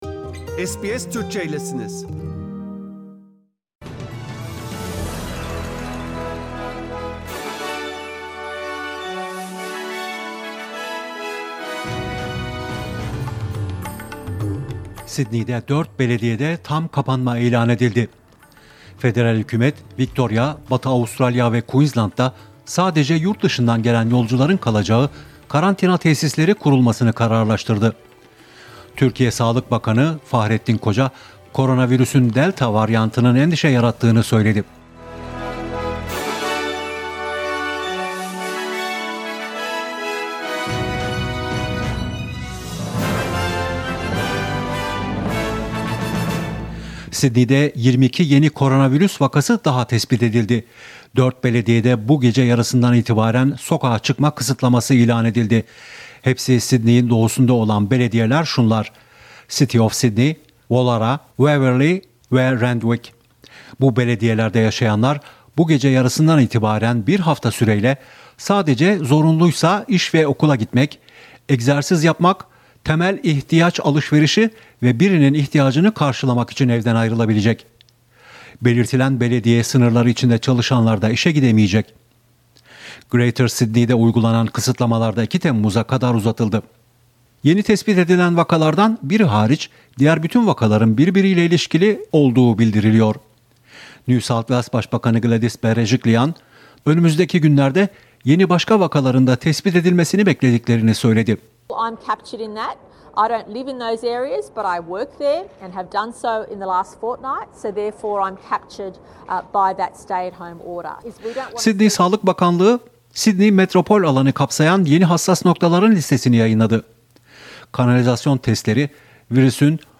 SBS Türkçe Haberler 25 Haziran